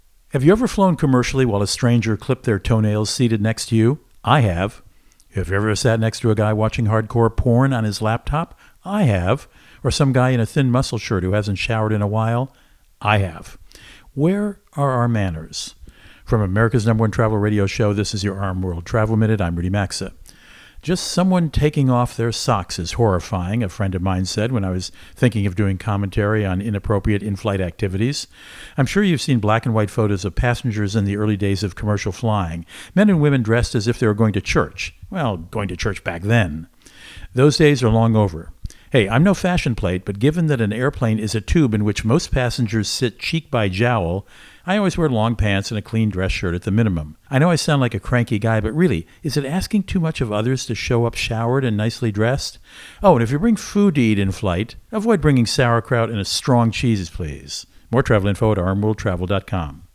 Co-Host Rudy Maxa | Do it Right, Have Good Manners While Flying